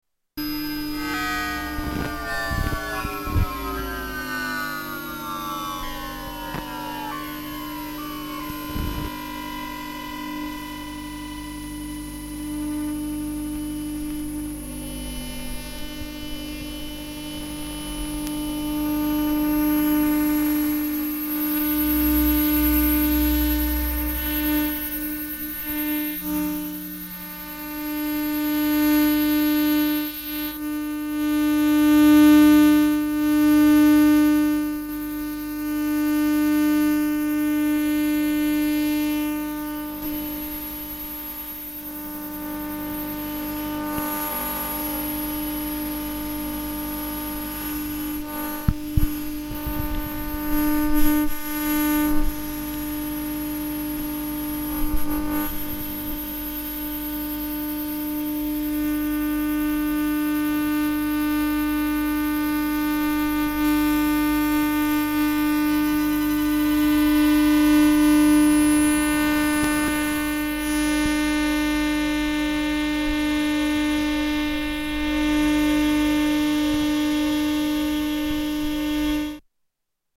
Subway arriving and leaving
Tags: Travel Sounds of Austria Austria Holidays Vienna